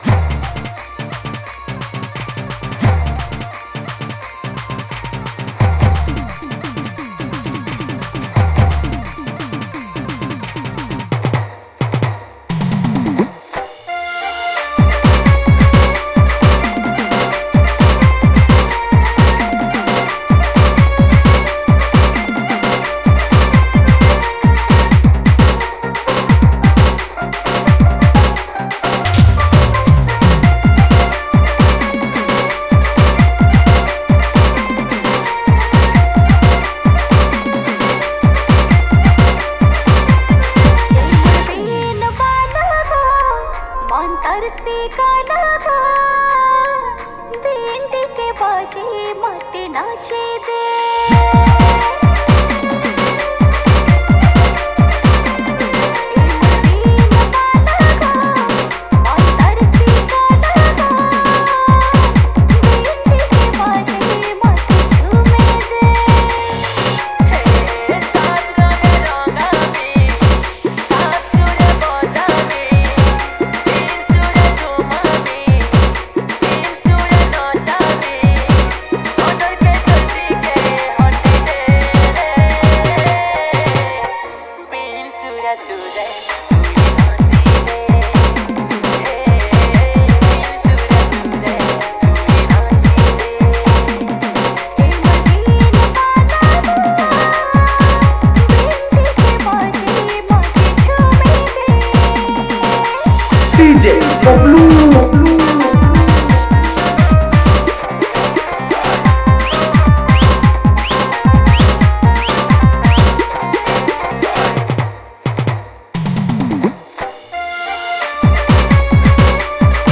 Category:  Sambalpuri New Dj Song 2019